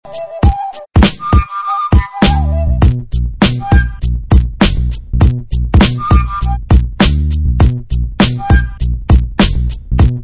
12 Styl: Hip-Hop Rok